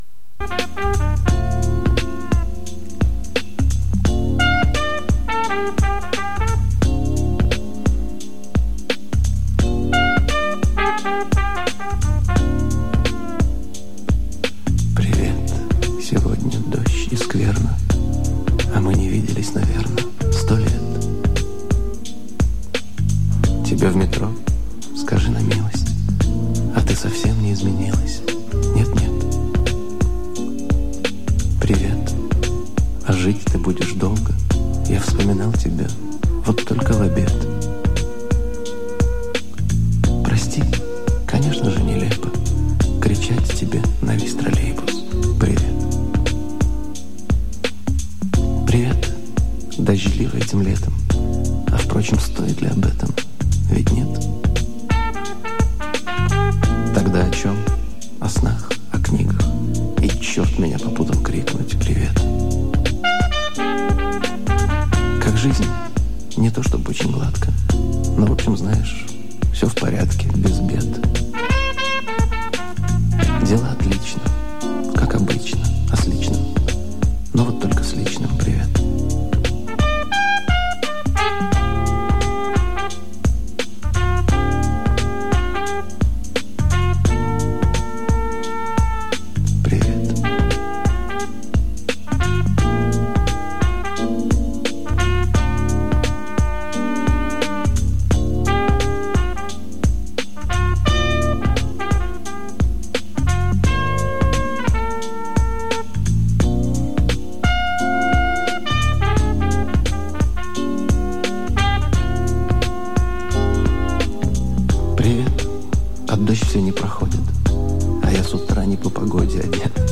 Своеобразный привет от Максима Леонидова и какая-то итальянская группа, может los del rio (mar)?
(оцифровка с кассеты)
Безусловно есть намек на известную "Макарену", но это может быть микс совершенно незнакомой группы (не LOS DEL RIO), если Вам более-менее знаком английский попробуйте найти по тексту (звучащему в песне) через поисковик.